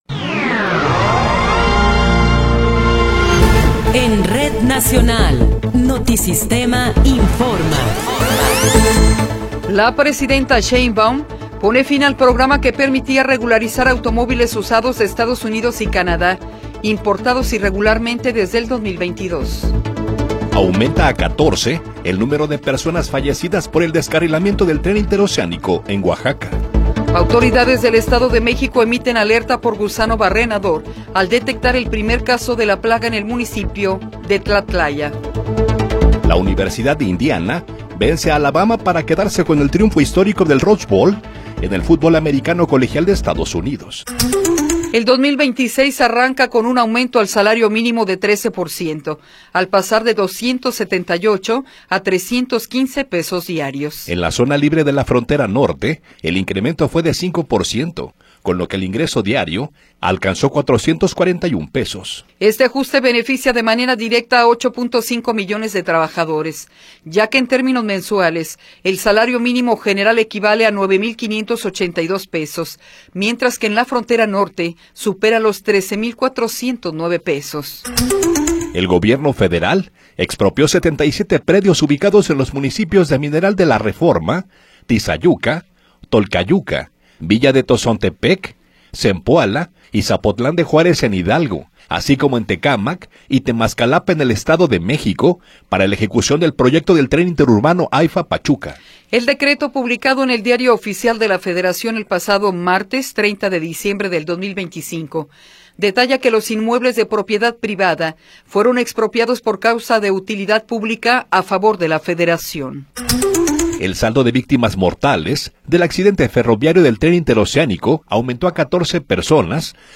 Noticiero 8 hrs. – 2 de Enero de 2026
Resumen informativo Notisistema, la mejor y más completa información cada hora en la hora.